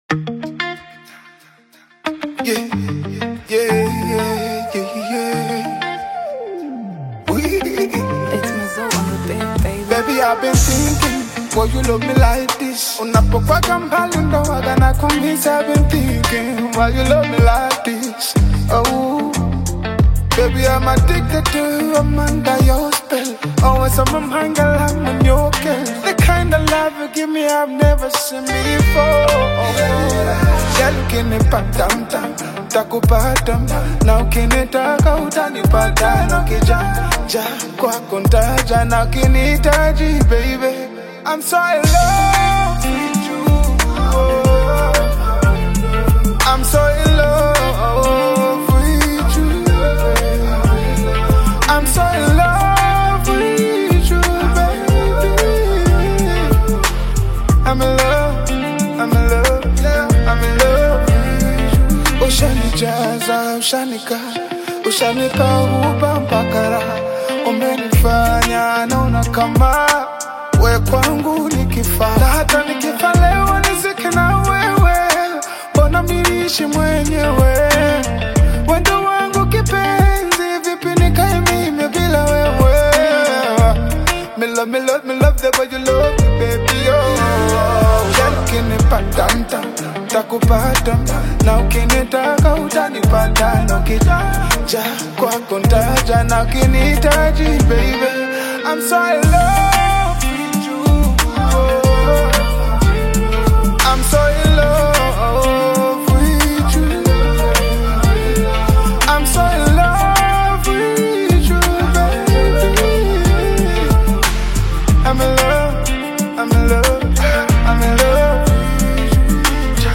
romantic Afro-R&B single